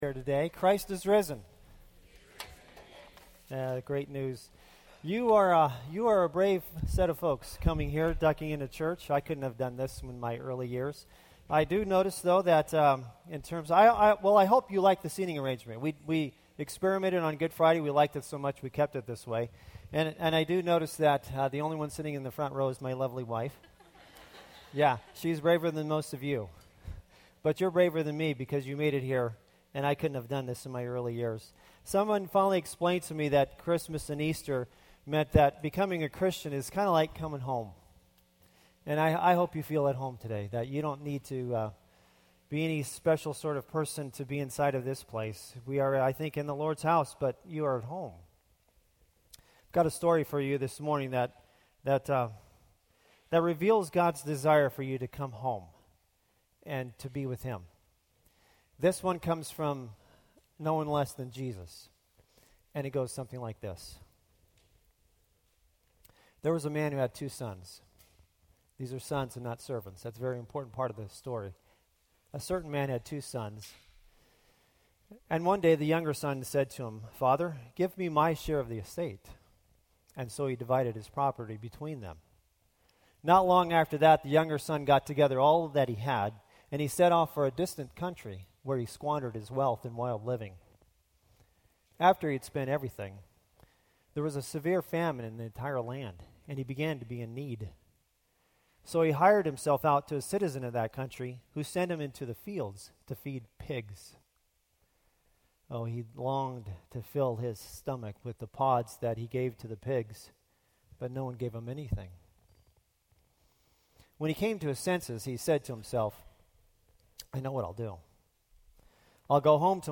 Sermons | Hope Community Church
Easter Service - The Prodigal Son